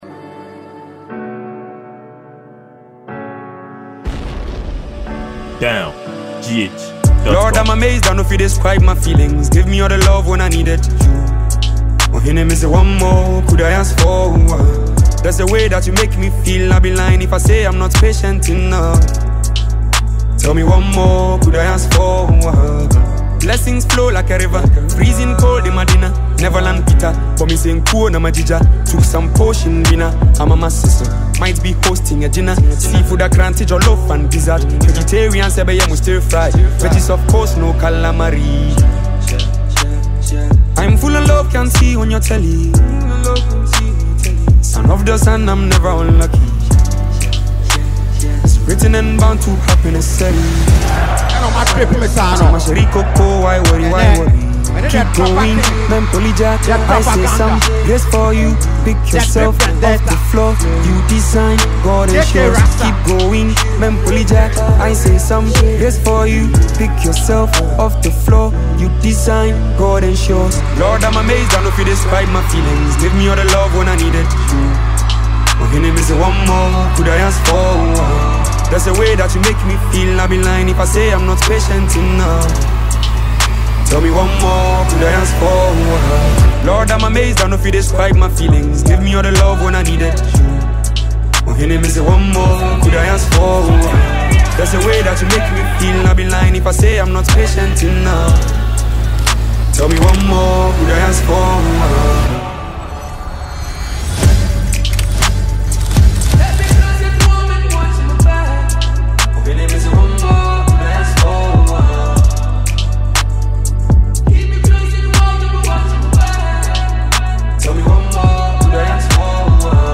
Ghanaian musician
afrobeat hiphop